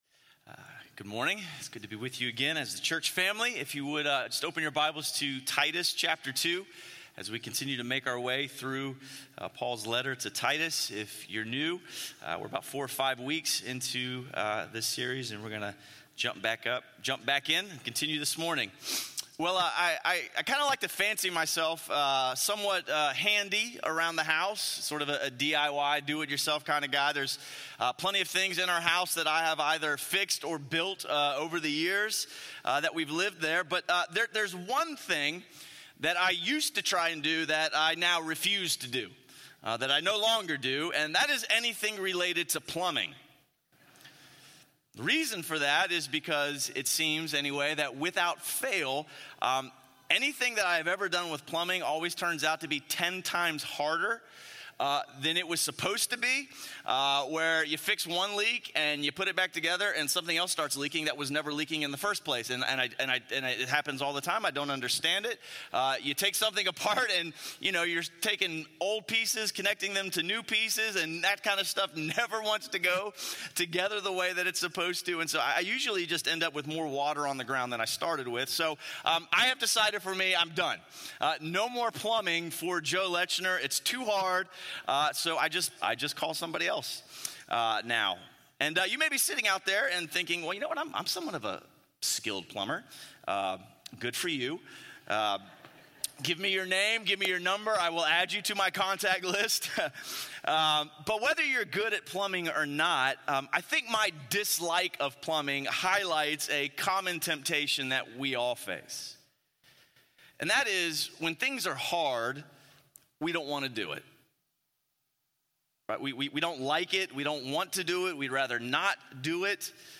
A message from the series "Good News to Good Life."